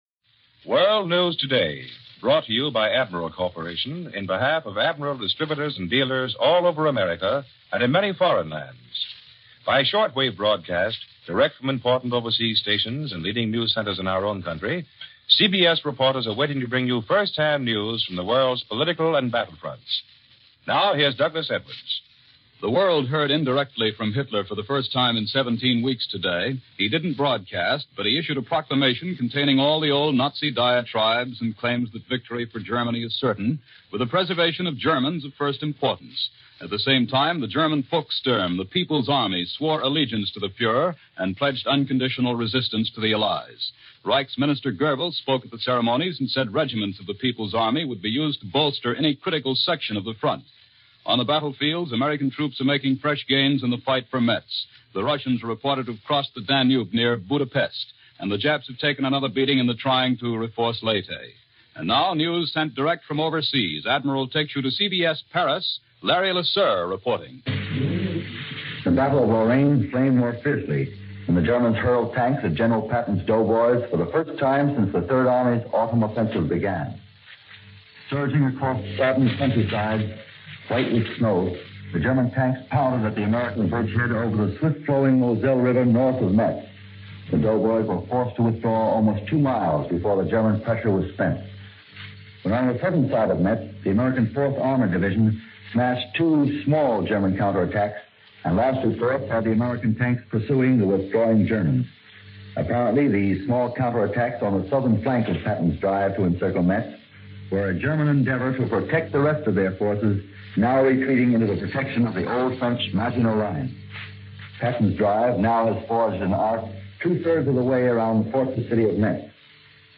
November 12, 1944 - Allies Pushing Forwar - Europe Planning Ahead - Hitler Proclaims - News for this day during World War 2 - 1944.